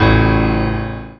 55y-pno08-e3.wav